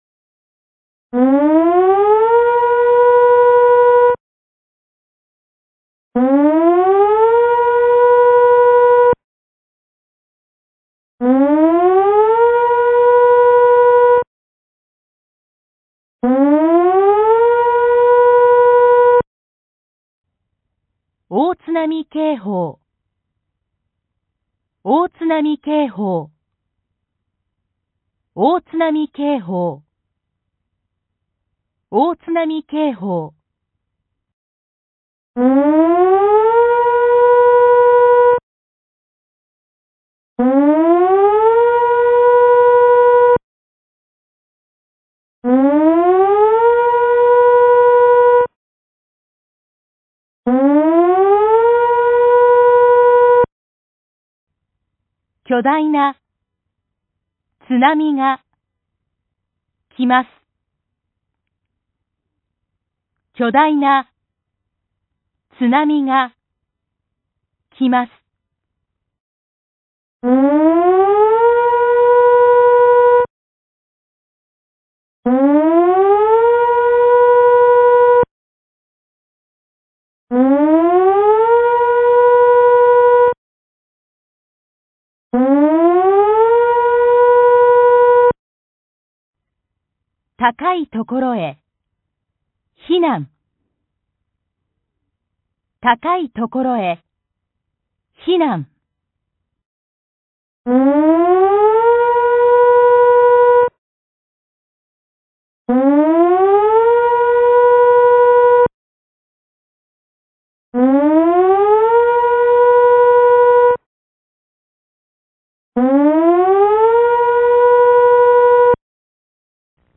根據氣象廳發布的大海嘯警報、海嘯警報、海嘯警報,設置的室外揚聲器將自動播放海嘯警報、避難勸告等緊急資訊。
1. 警報(4次)
約3秒(約2秒秋)
※播出1～10的3套。另外,各套餐還播放了不同語言(英語、華語、韓語)催促避難的語言。
※第11個汽笛只在第3局播放。
大海嘯警報音源(10m<海嘯的預測高度)(音樂文件(MP3):7314KB)